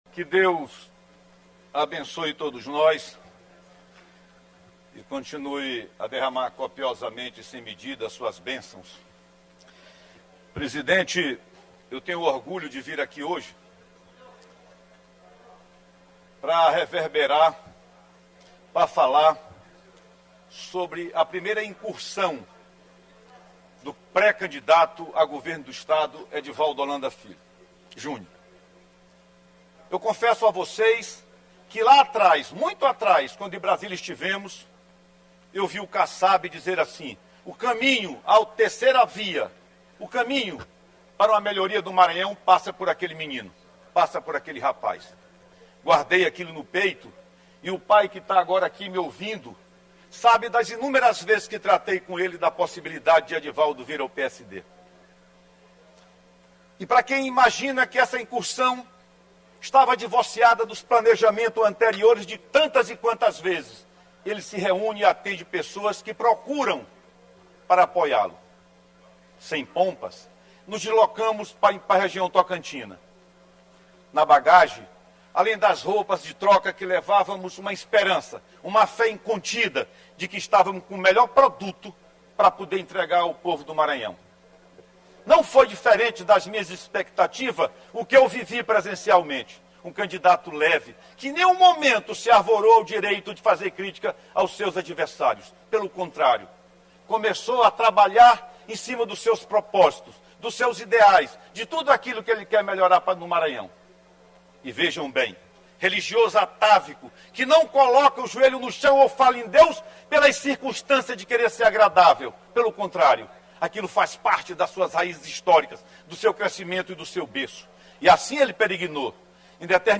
O deputado César Pires destacou, na sessão desta terça-feira (05), a visita que fez com o pré-candidato a governador, Edivaldo Holanda Júnior, e o presidente estadual do PSD, deputado federal Edilázio Júnior, à Região Tocantina.
Deputado-Cesar-Pires-.mp3